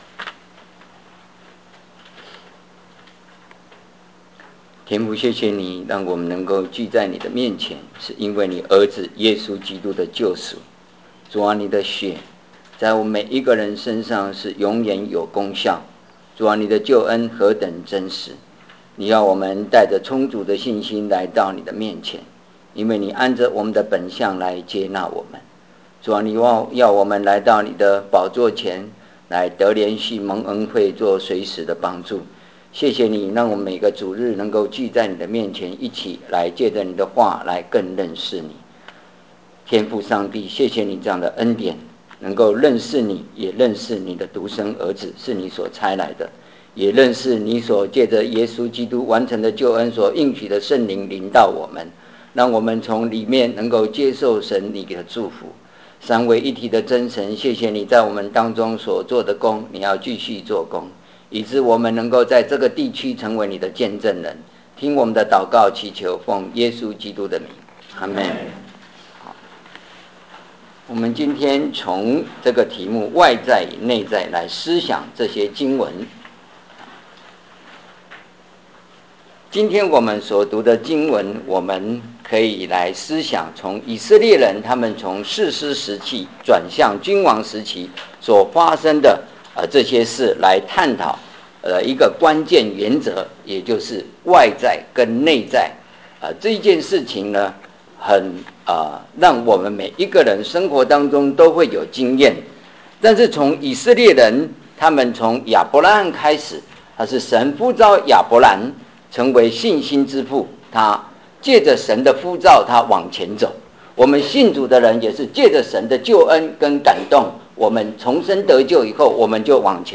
所有布道录音现都已转换成MP3格式，这样能用本网站内置播放器插件播放。